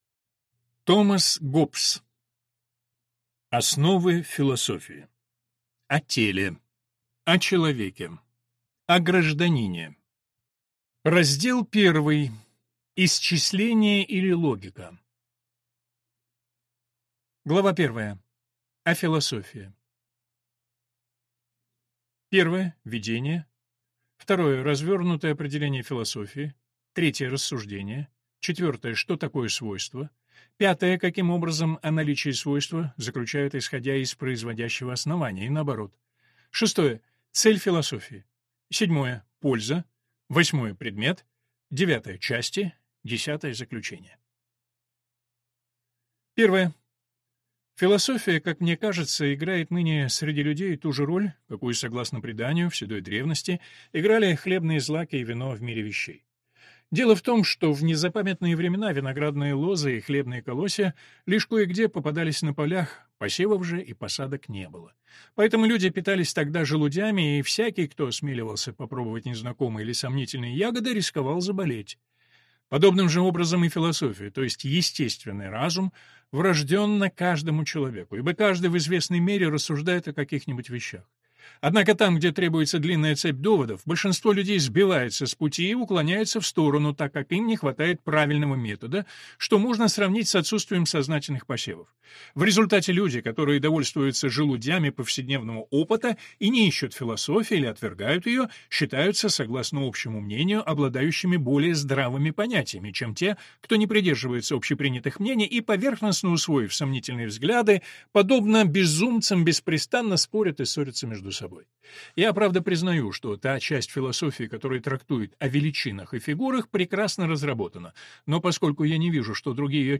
Аудиокнига Основы философии (о теле, о человеке, о гражданине) | Библиотека аудиокниг